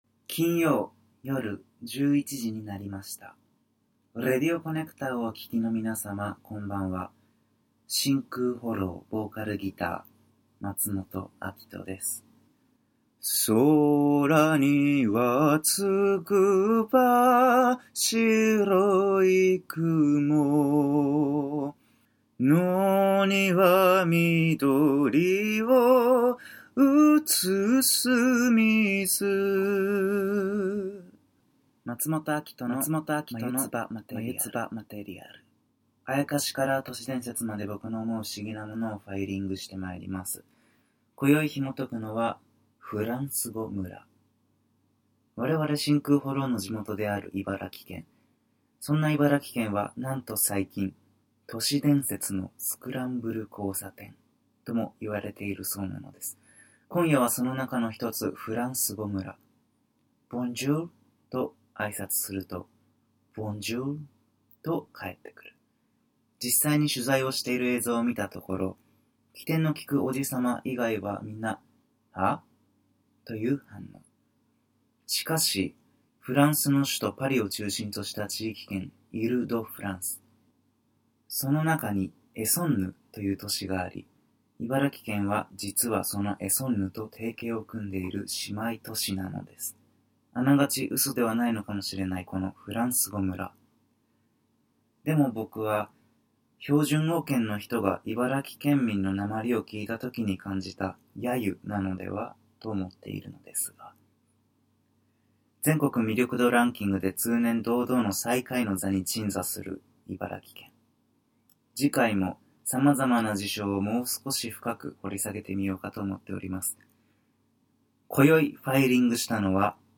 高校球児の校歌斉唱の如く清々しく”茨城県民歌”！
ラ行をぐるんぐるん巻けるだけでないおフランス語。